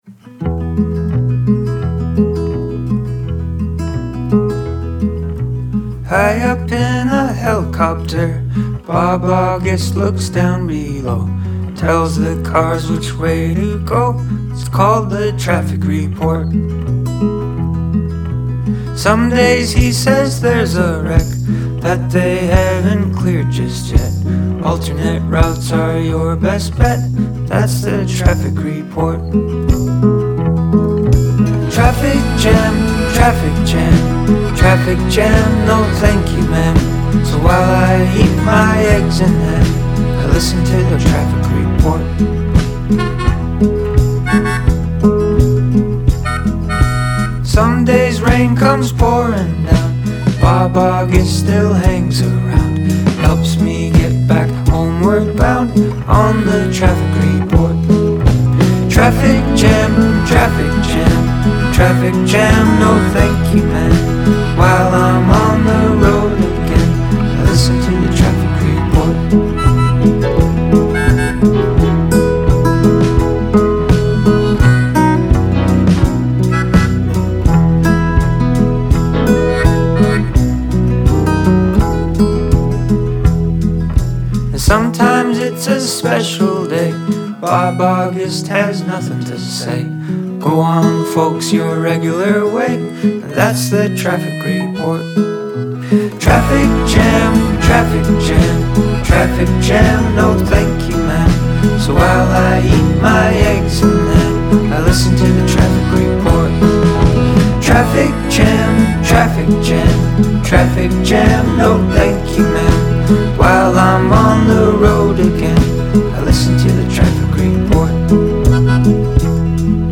kids type song